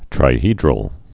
(trī-hēdrəl)